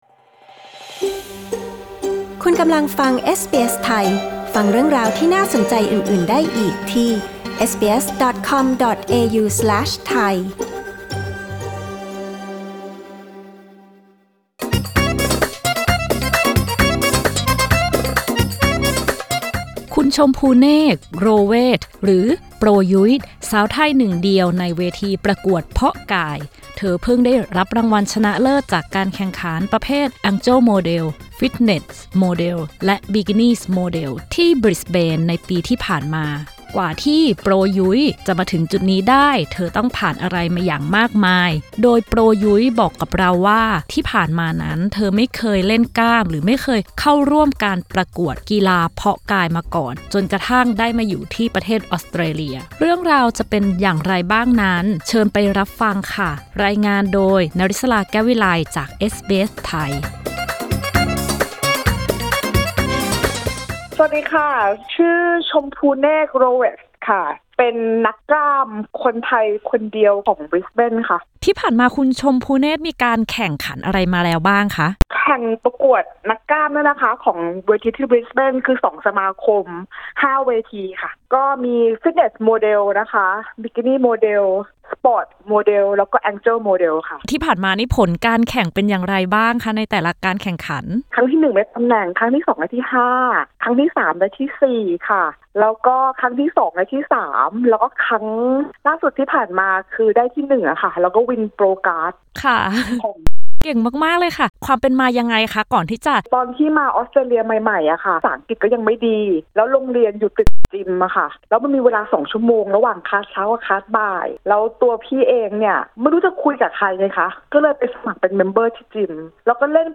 กดปุ่ม 🔊 ที่ภาพด้านบนเพื่อฟังสัมภาษณ์แบบเต็ม